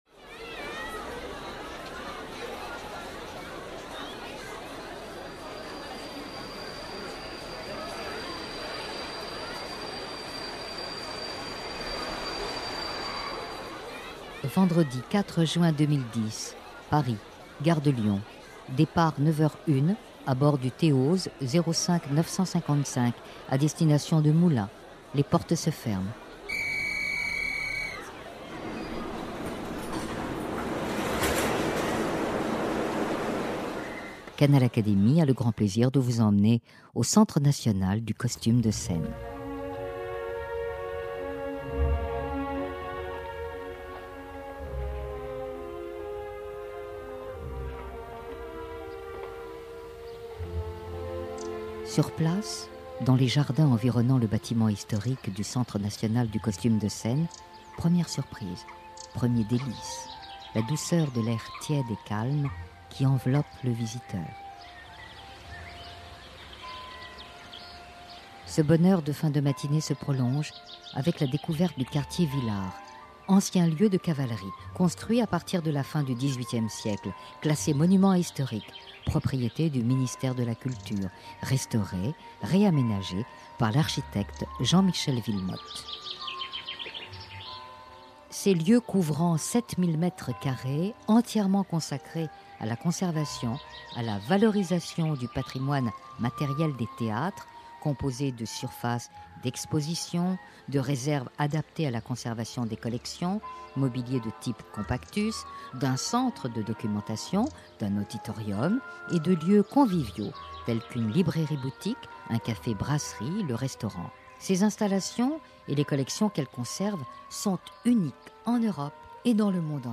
Découvrez quelques-uns des 9000 costumes de scène qui font partie des plus belles collections du monde, avec les explications de Christian Lacroix et Maurizio Galante.
Dans ce reportage, destination : Le Centre National du Costume de Scène, CNCS, à Moulins dans l'Allier.